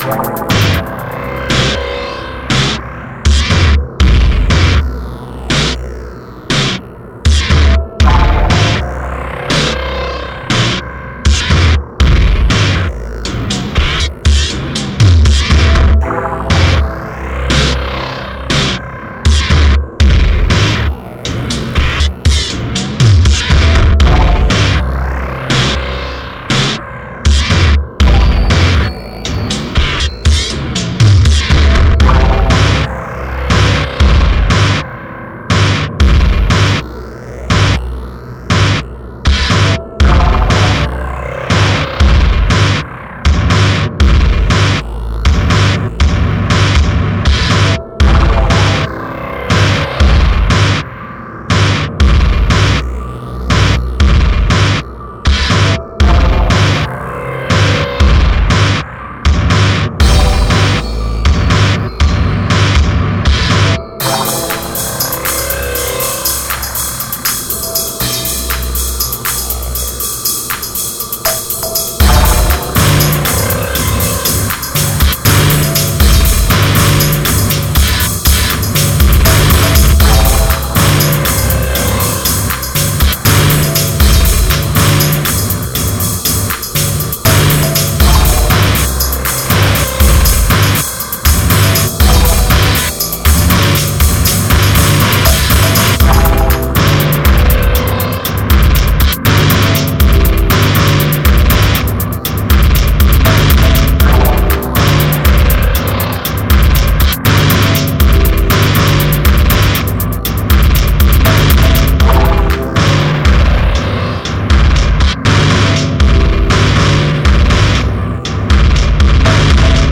Default some Breaks and techno tracks